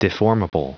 Prononciation du mot deformable en anglais (fichier audio)
Prononciation du mot : deformable